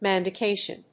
manducation.mp3